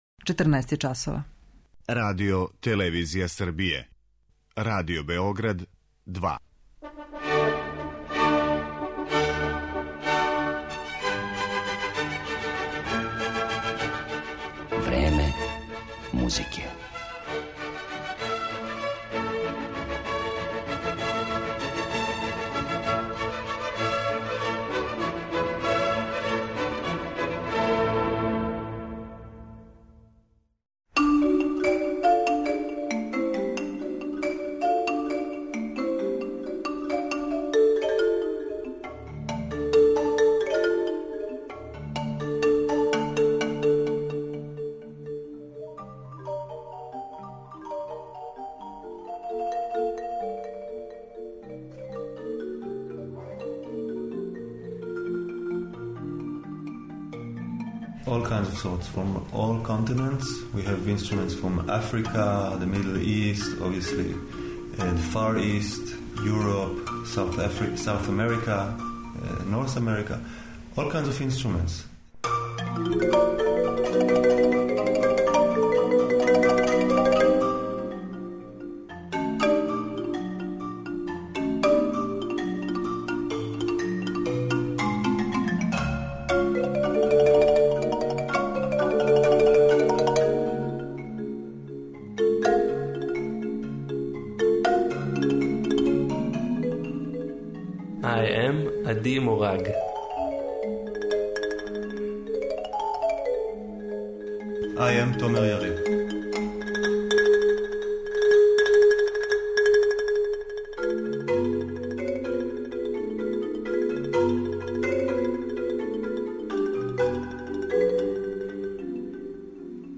Овом изузетном ансамблу посвећено је данашње Време музике , у коме ћемо емитовати и интервју снимљен са извођачима приликом једног од њихових гостовања у Београду.